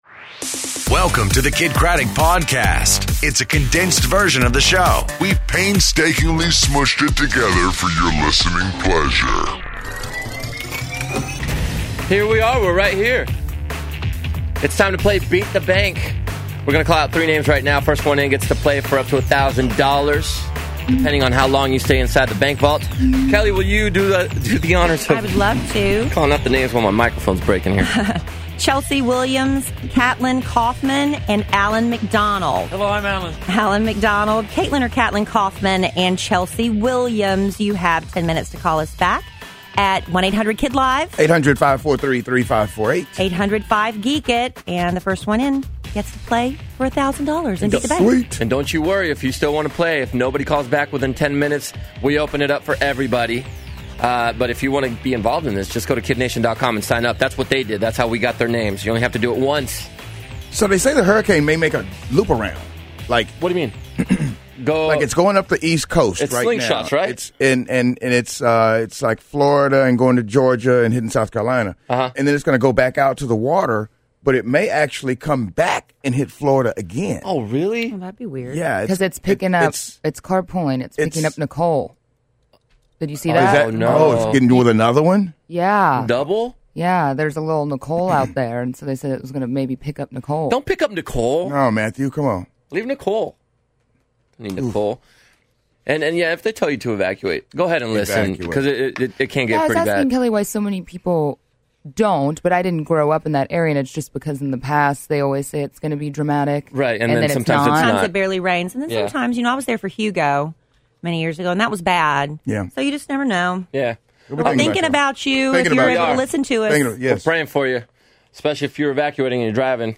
Arsenio Hall In Studio, Feel Good Friday, And New Music!